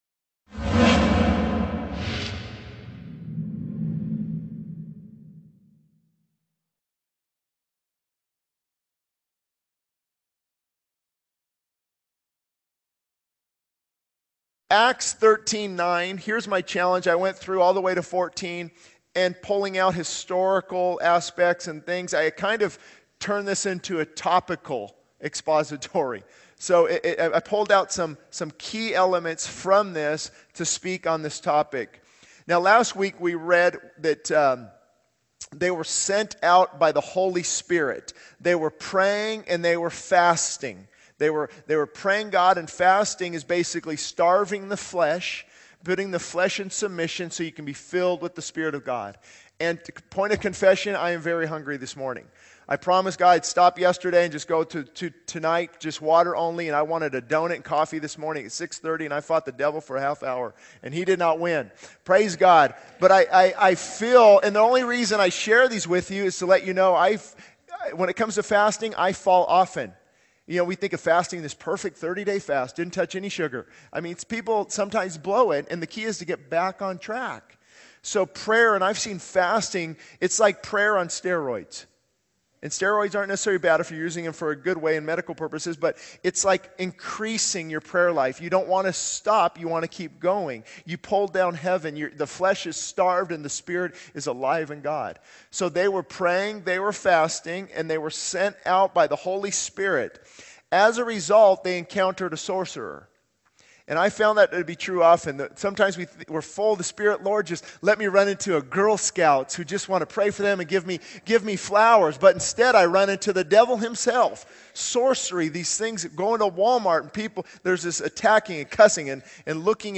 This sermon delves into the importance of spiritual disciplines like prayer and fasting in a healthy church and individual Christian life. It emphasizes the need to remove distractions, engage in spiritual warfare, and prioritize joy and the Holy Spirit. The sermon also highlights the significance of baptism as a declaration of faith and the necessity to end excuses hindering spiritual growth.